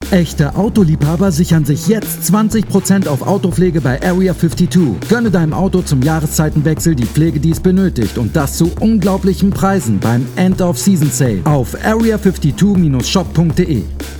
Sprecher, Synchronsprecher, Schauspieler